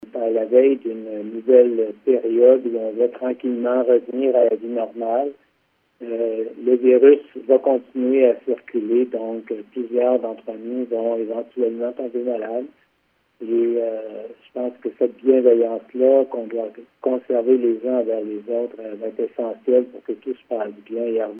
La bienveillance sera essentielle pour que tout se passe bien, a mentionné en point de presse, vendredi après-midi, le dr Yv Bonnier-Viger, directeur régional de la santé publique de la Gaspésie-les Îles.